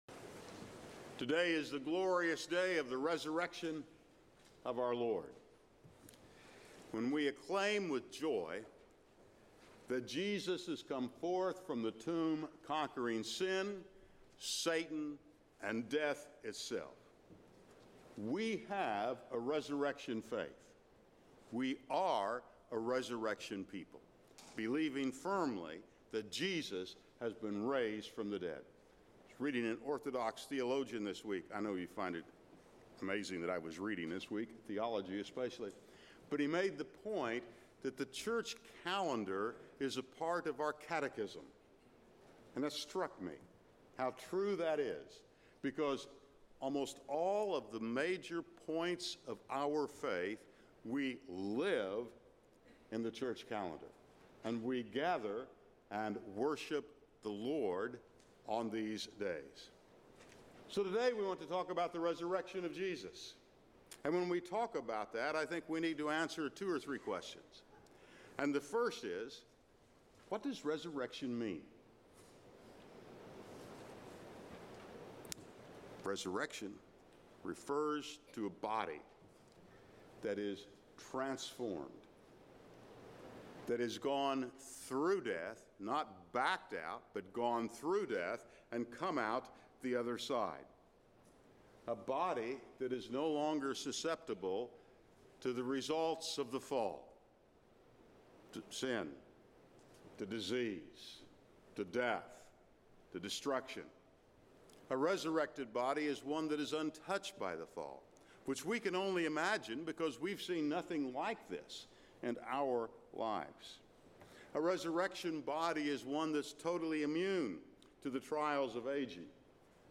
Raised with Christ (Easter Sunrise service)